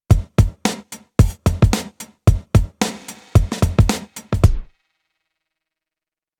Grid, Spring and Delay keep resounding, but Mother doesn’t. It might be an effect you’re looking for, but 99% of the time I’d like my reverb to keep reverberating after punching in.
Spring.mp3